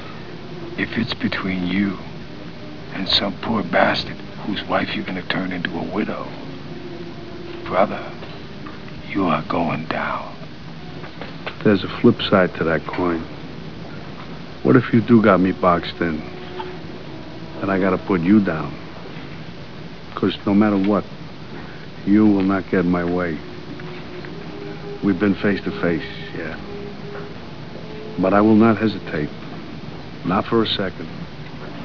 Bobby DeNiro and Al Pacino are sitting in the coffe shop and talking about dicipline.